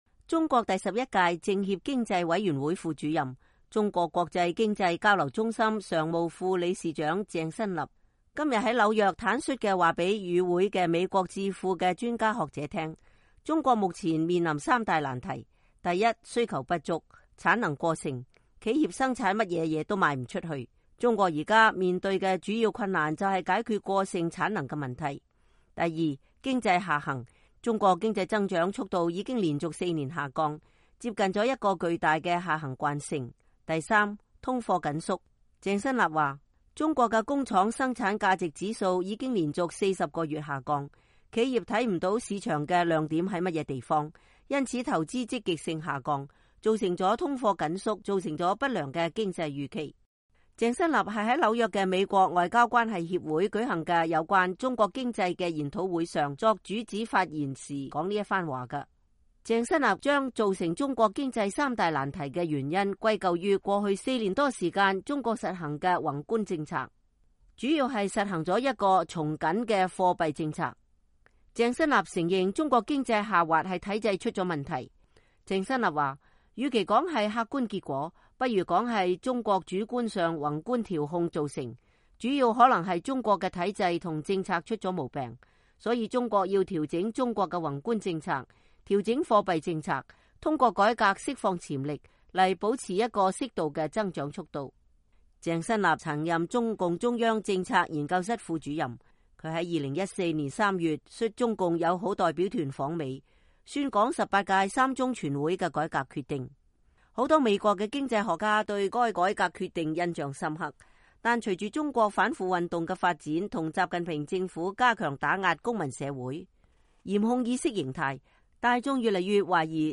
鄭新立是在紐約的美國外交關係協會舉行的有關中國經濟的研討會上作主旨發言時發表上述評論的。